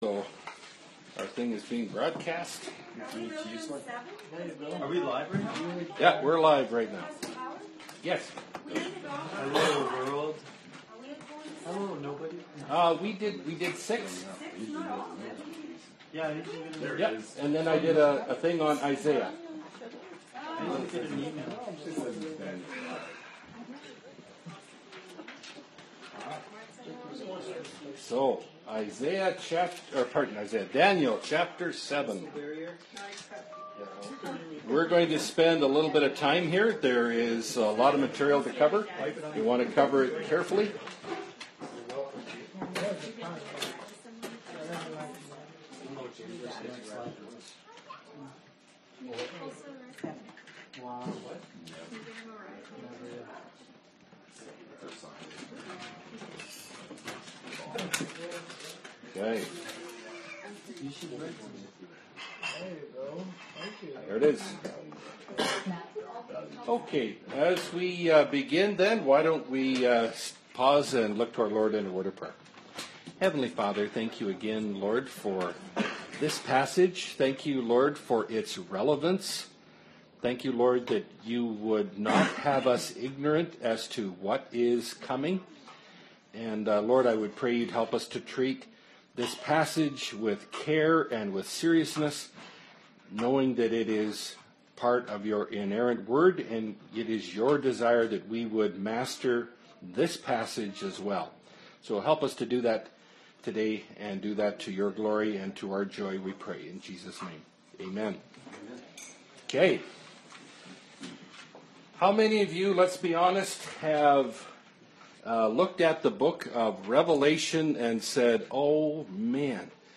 Bible Study – Daniel 7 – Part 1 of 3 (2017)
Daniel Category: Bible Studies Key Passage: Daniel 7 Download this Audio File